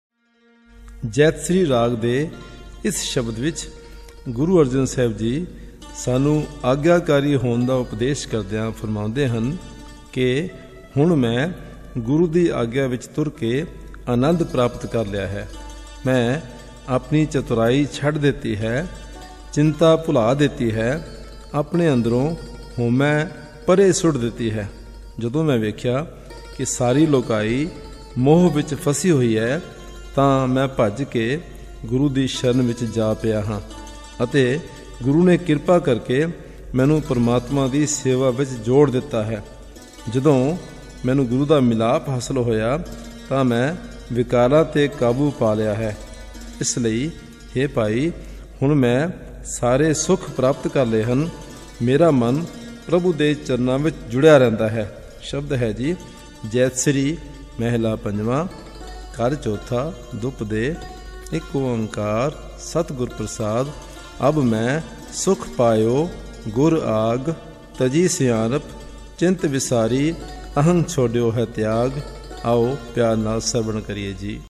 Commentary on this Shabad
ab-mai-sukh-payo-gur-aagh-jaitsri-commentry.mp3